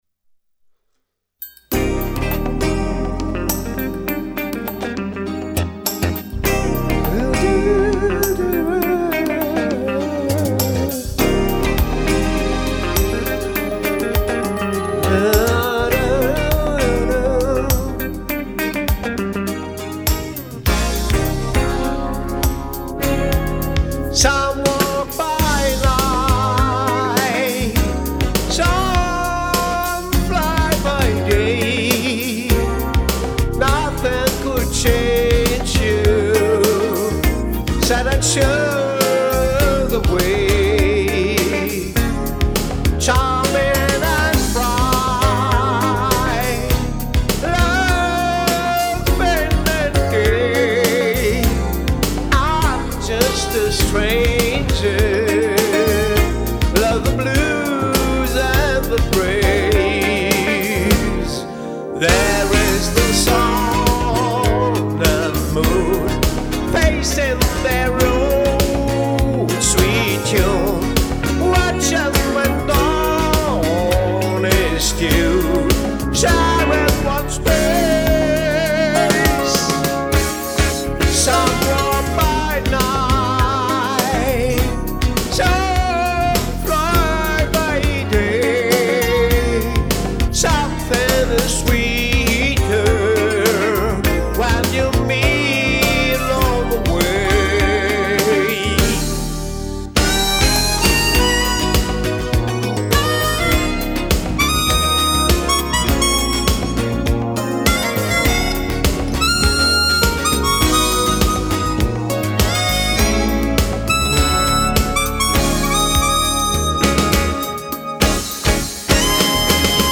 Style: Jazz
Inkl. Solopart unisono zum Synthesizersolo gesungen
Microphone: Rohde NT-1